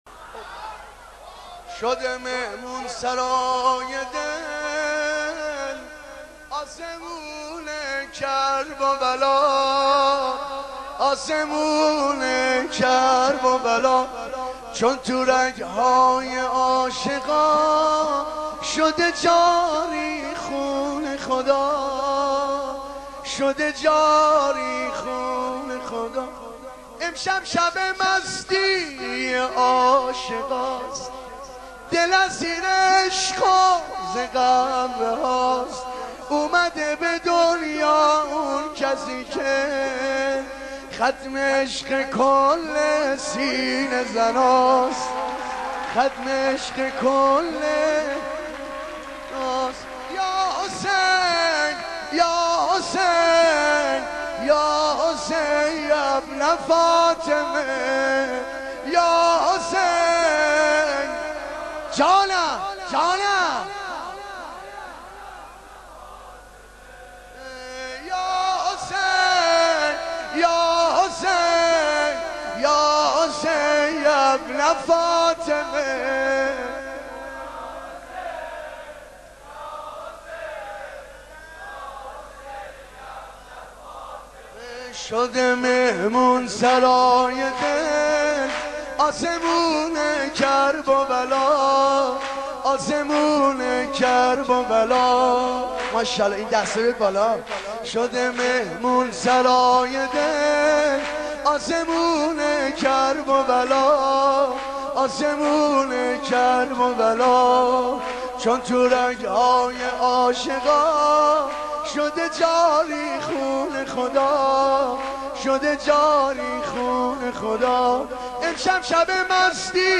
مولودی خوانی
در شب میلاد امام حسین(ع)